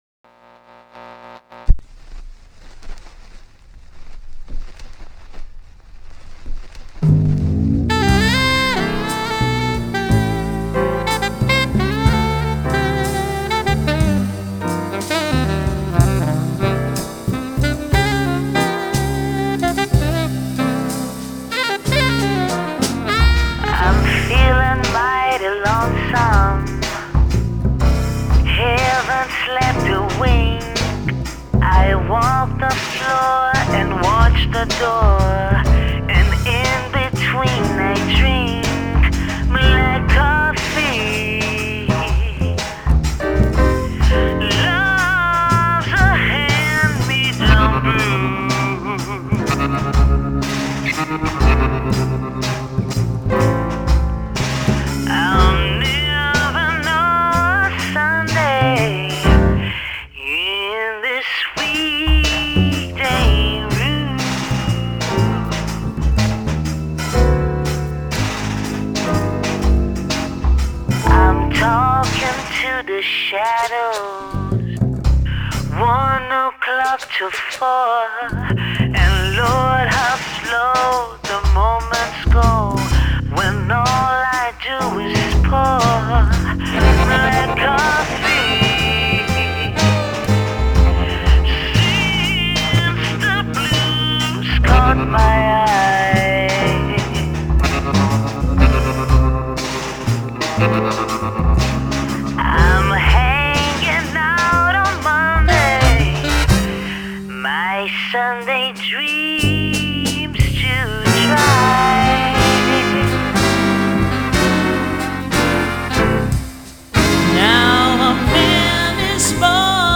Genre: Blues, Vocals